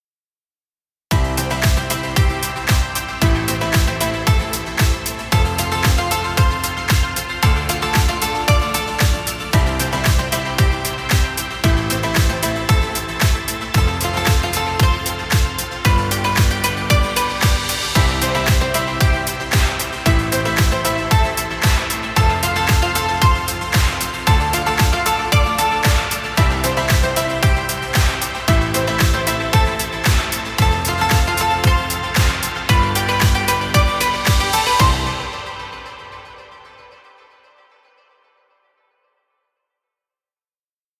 Happy inspirational music. Background music Royalty Free.